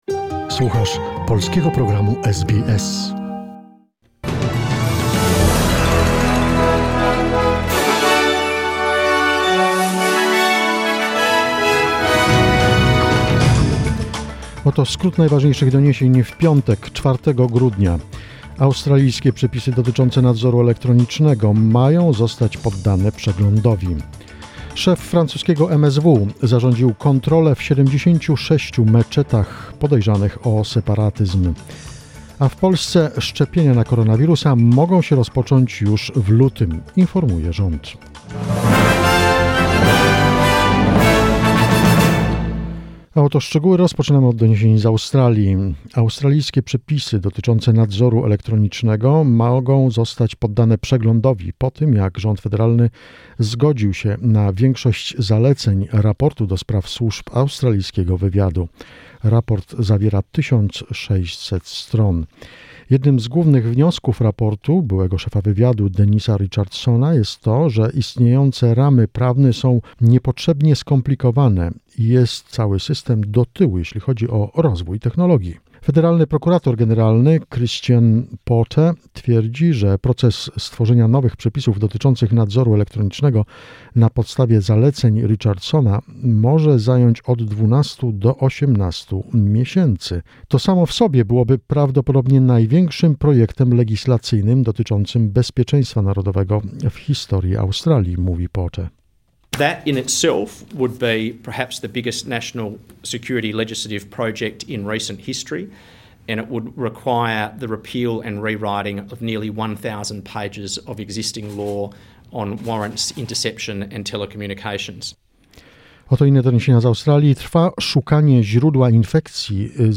SBS News, 4 December 2020